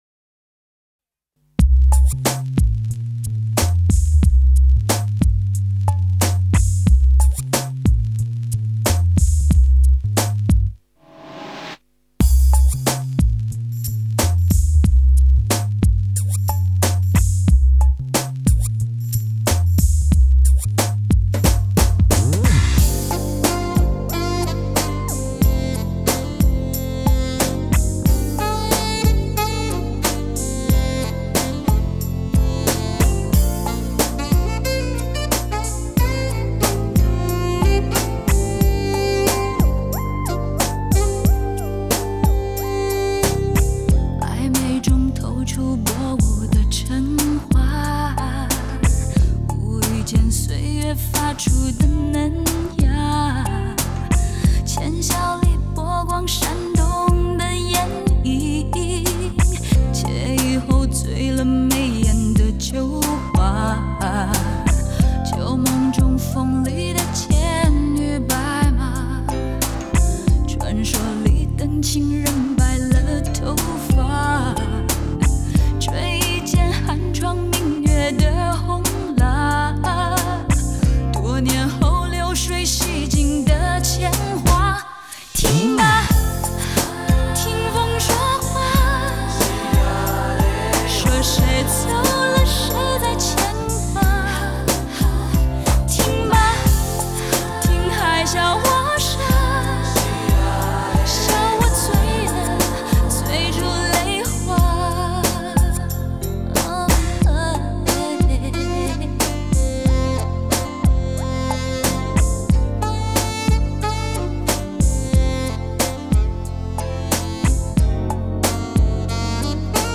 这张专辑主要是以节奏蓝调为主，也就是我们通常所说的R&B音乐。